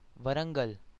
pronunciation) is a city in the Indian state of Telangana and the district headquarters of Warangal district.